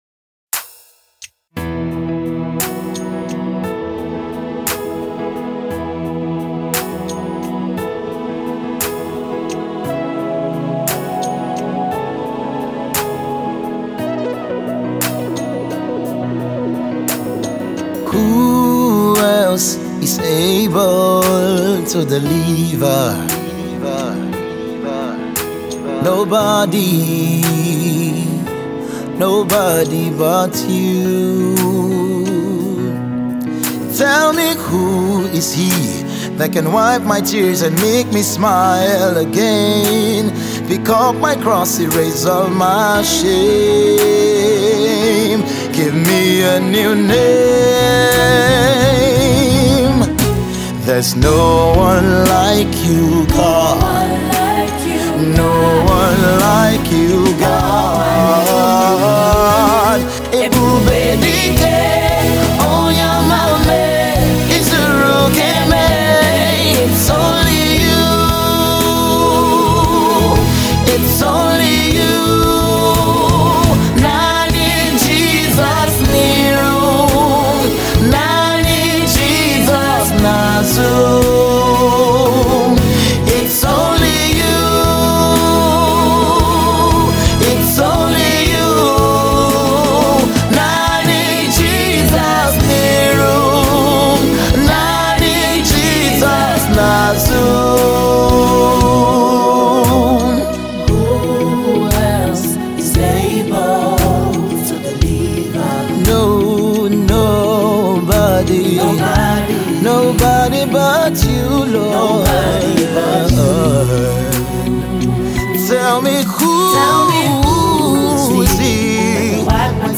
Rock Gospel piece
captivating fresh vocals